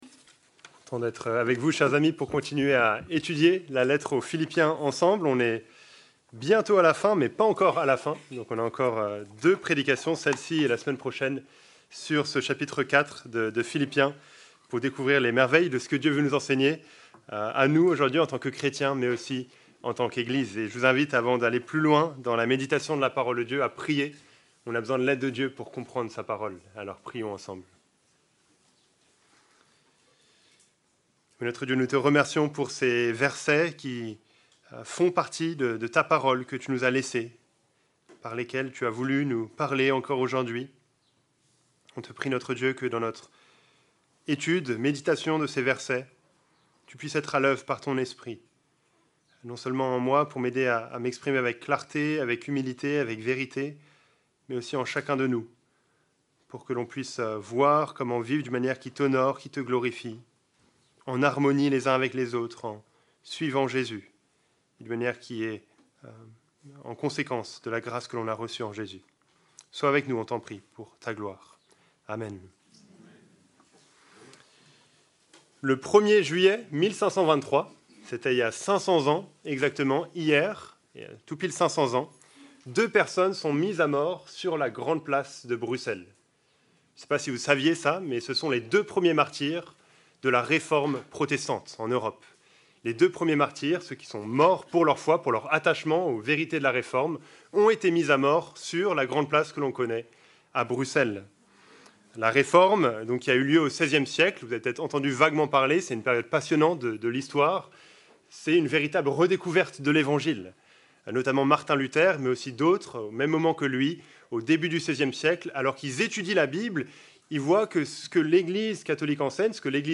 Prédication-du-culte-du-2-juillet-2023.mp3